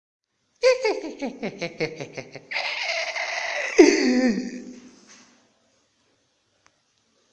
Laugh Off Sound Button: Unblocked Meme Soundboard
Laugh Off Sound Effects